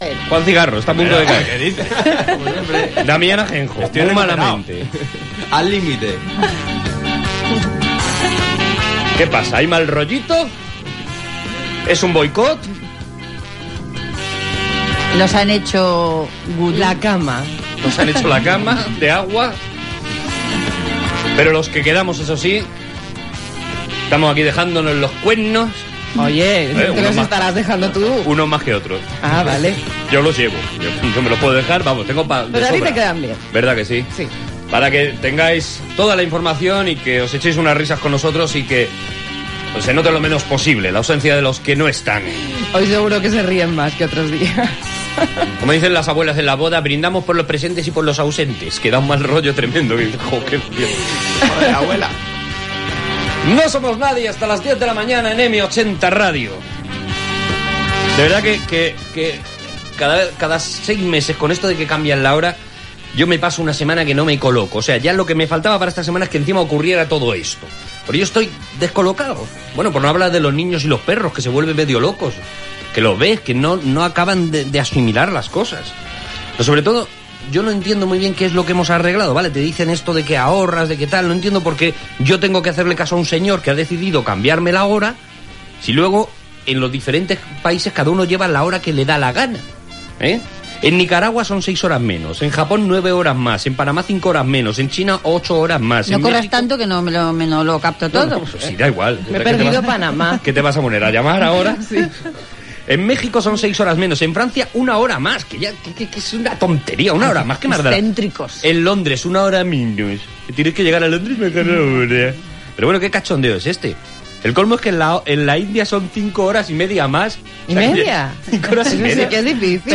Arturo González-Campos substitueix a Pablo Motos en la presentació del programa.
Entreteniment